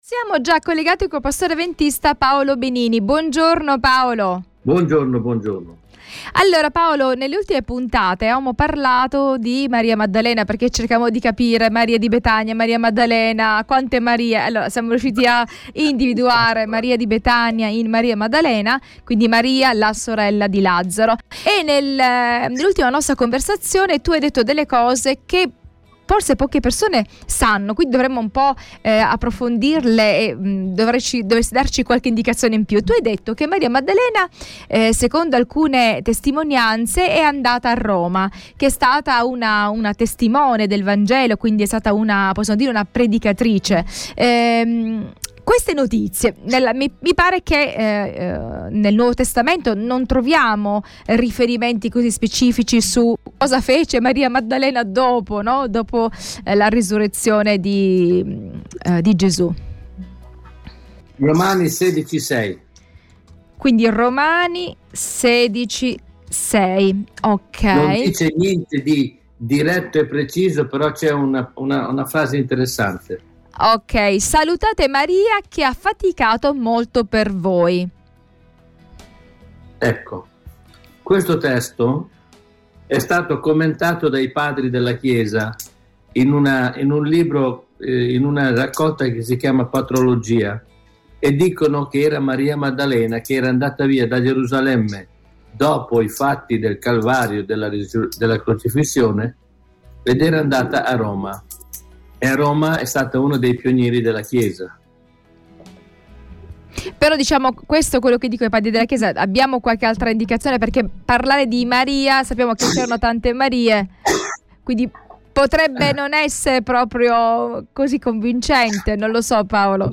pastore avventista.